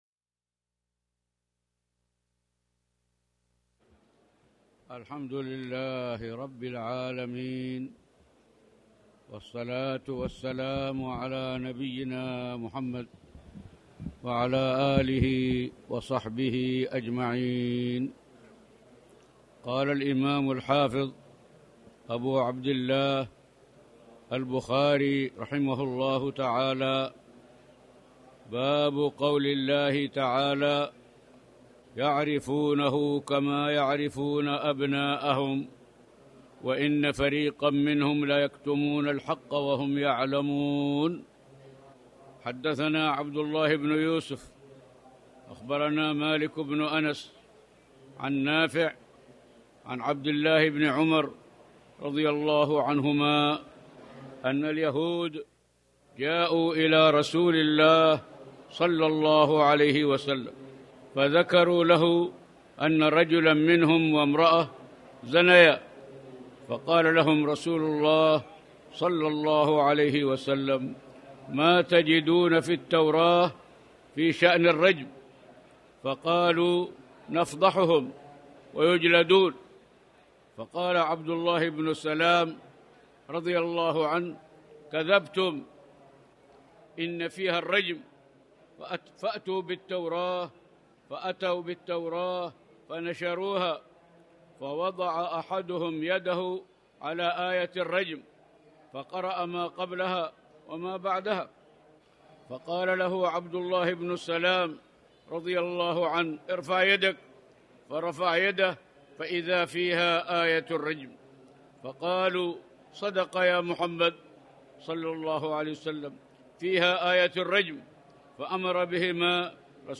تاريخ النشر ٣٠ ربيع الثاني ١٤٣٩ هـ المكان: المسجد الحرام الشيخ